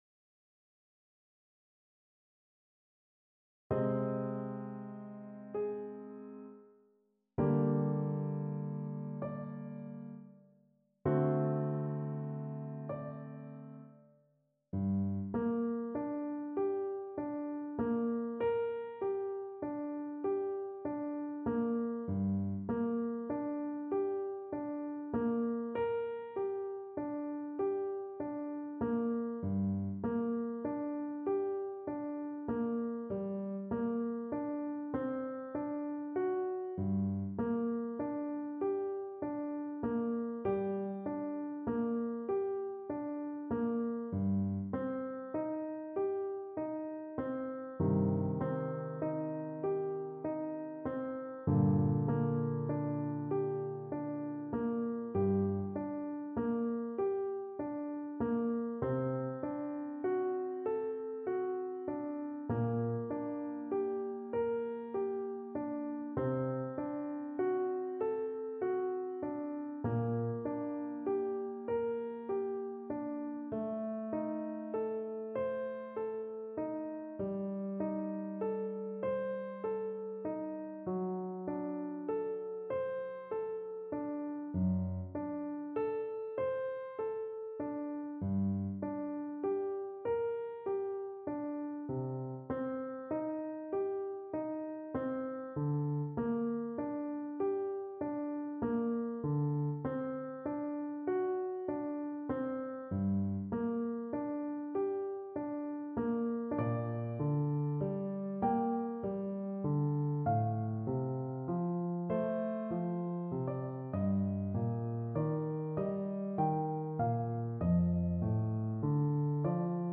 Andante cantabile =46
Classical (View more Classical French Horn Music)